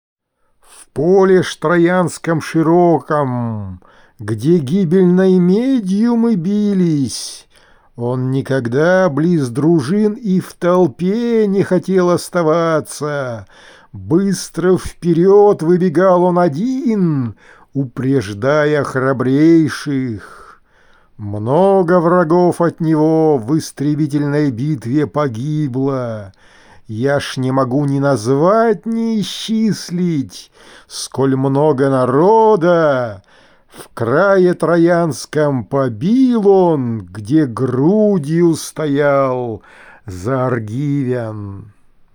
Одиссея. Песня одиннадцатая: Декламация